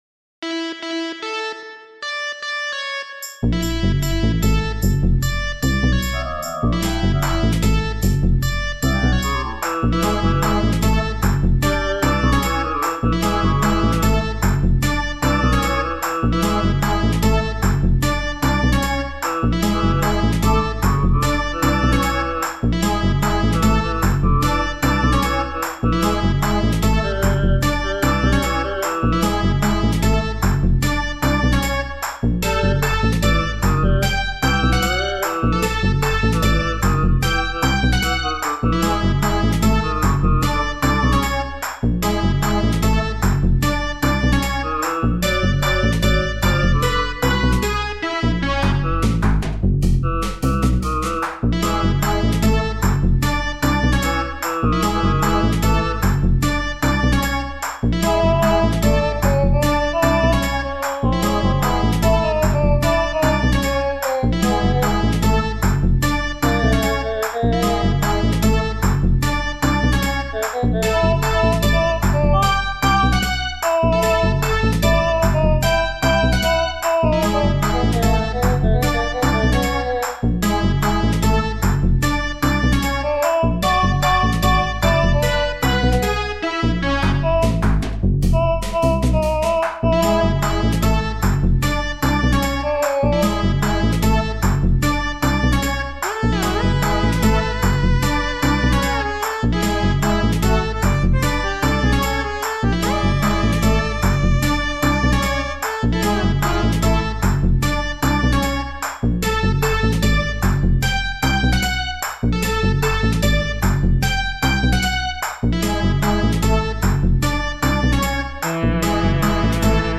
made in BeepBox